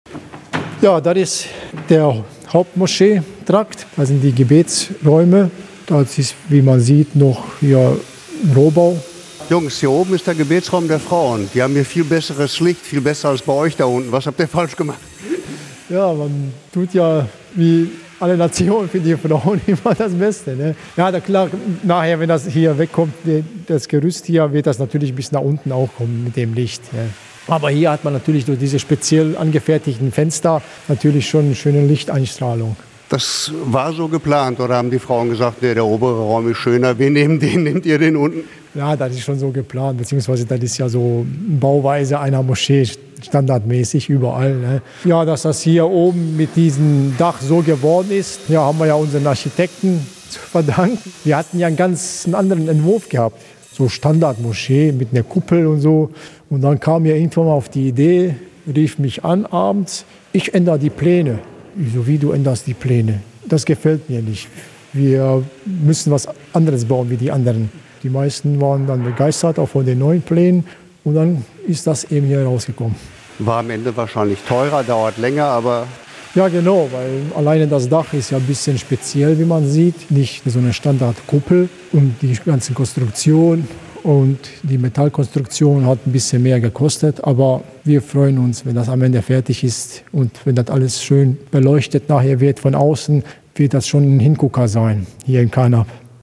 moschee-karnap-reportage---gebetsraum-fuer-frauen-schoener-als-fuer-maenner.mp3